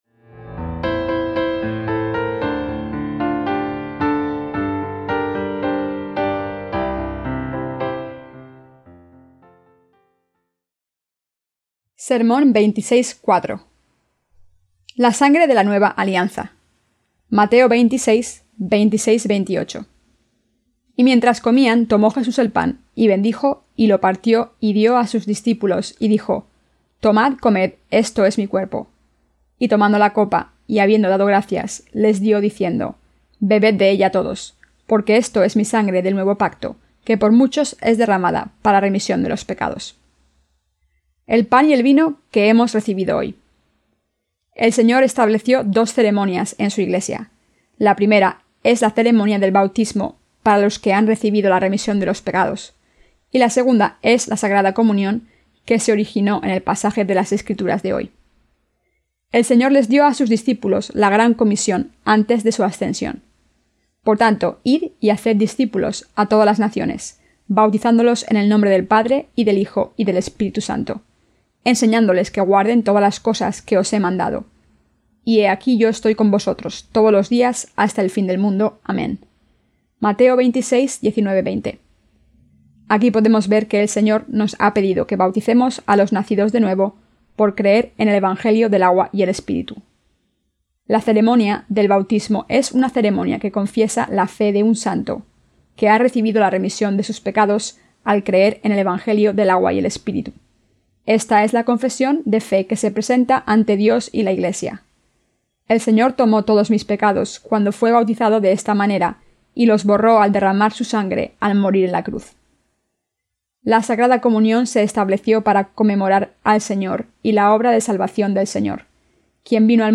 SERMONES SOBRE EL EVANGELIO DE MATEO (VI)-¿A QUIÉN SE LE PRESENTA LA MEJOR VIDA?